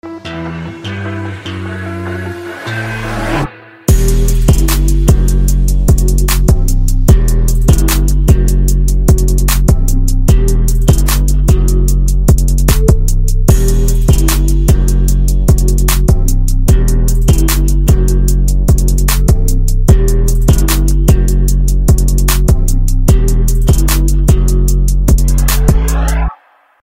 Categories: Instrumental